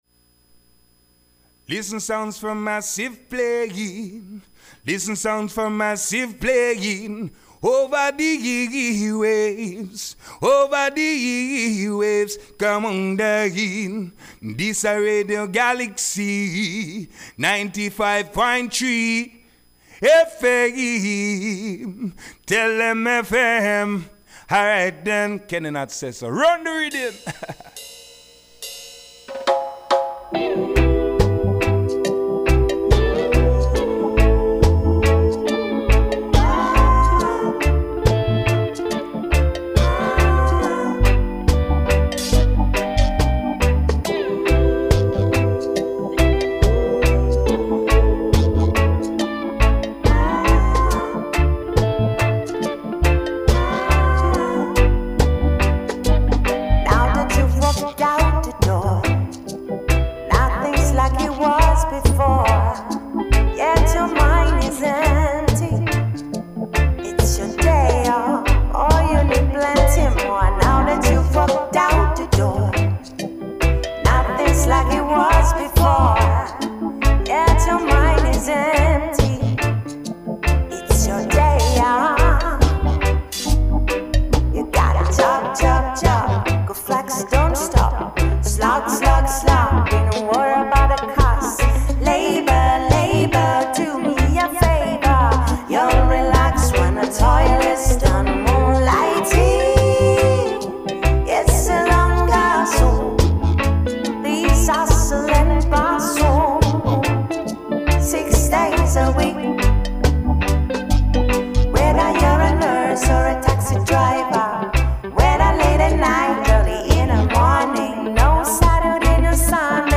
reggaephonique
enregistré lundi 6 octobre dans les studios